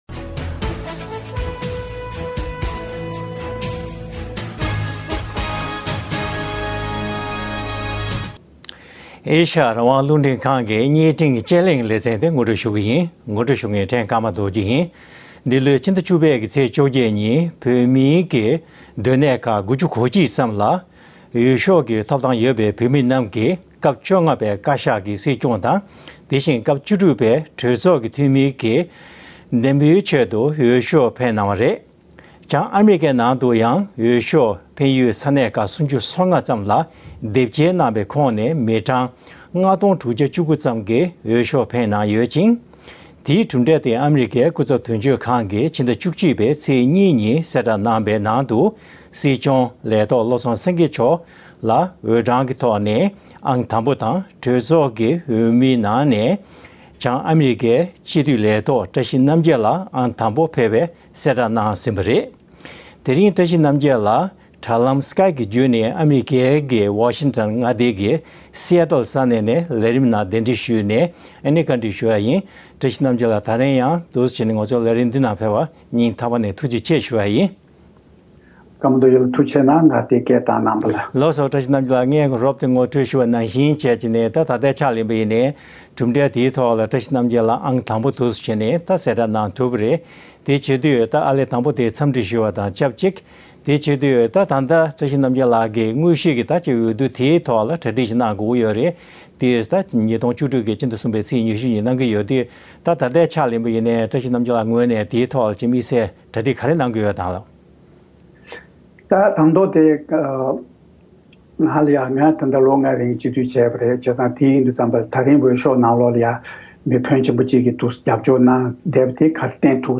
བྱང་ཨ་རིའི་སྤྱི་འཐུས་བཀྲ་ཤིས་རྣམ་རྒྱལ་ལགས་ཀྱི་ལྷན་༢༠༡༦ལོའི་སྲིད་སྐྱོང་དང་སྤྱི་འཐུས་འོས་བསྡུའི་སྐོར་གླེང་བ།
དྲ་ལམ་ skype བརྒྱུད་ནས་ཨ་མི་རི་ཀའི་Seattle ནས་